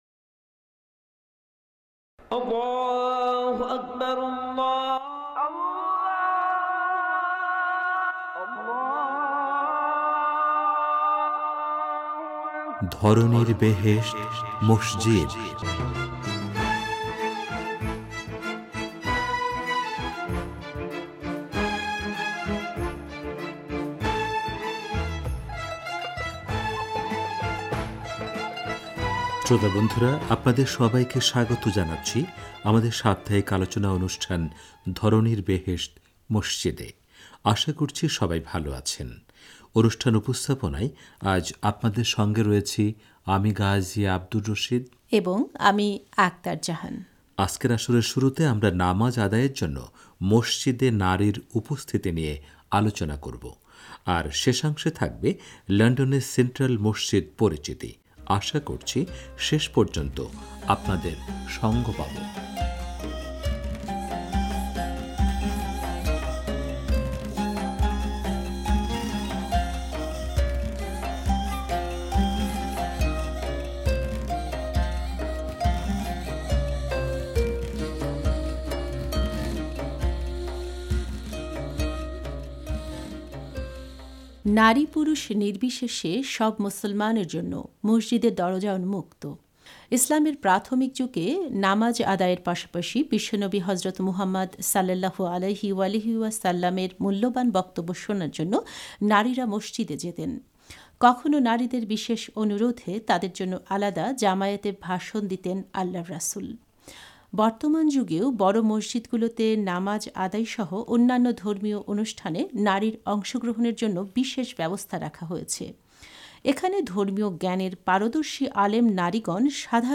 সাপ্তাহিক আলোচনা অনুষ্ঠান- ধরণীর বেহেশত মসজিদে। আজকের আসরের শুরুতে আমরা নামাজ আদায়ের জন্য মসজিদে নারীর উপস্থিতি নিয়ে আলোচনা করব।